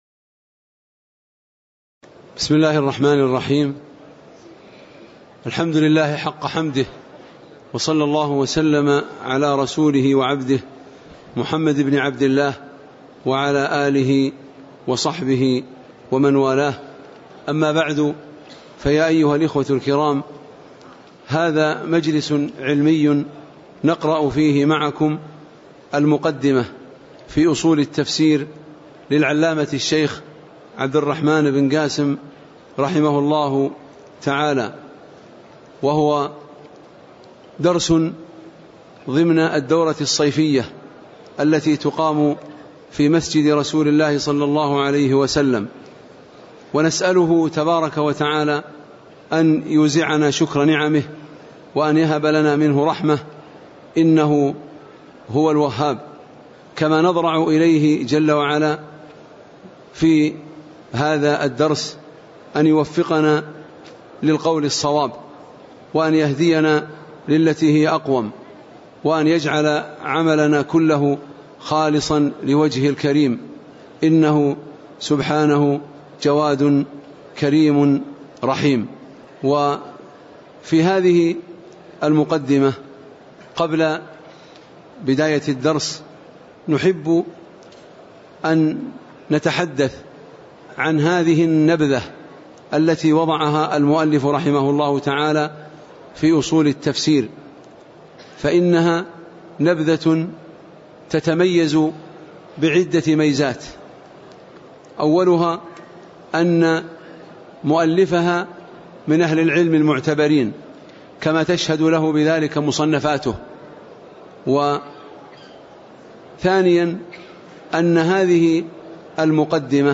تاريخ النشر ٩ شوال ١٤٣٩ هـ المكان: المسجد النبوي الشيخ